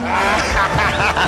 "(Turboman laughs)"
turboman_laughs.mp3